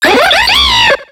Cri de Rhinolove dans Pokémon X et Y.